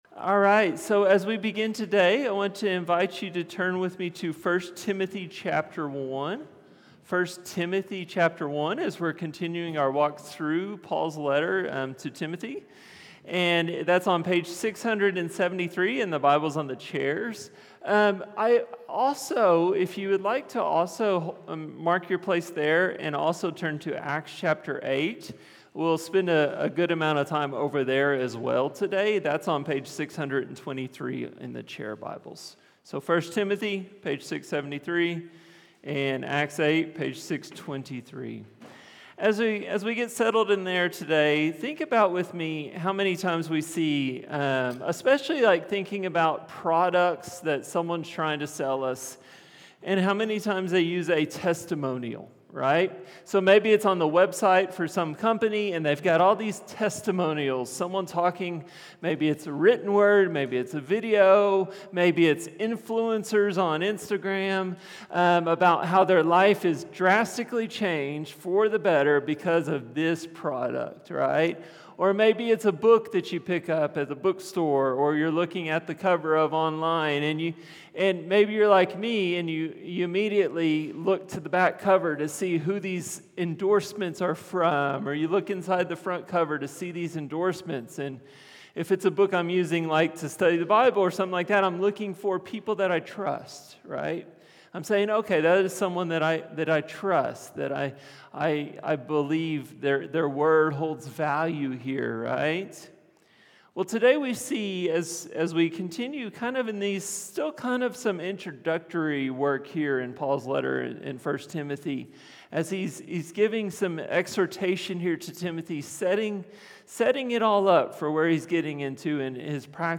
A message from the series "1 Timothy."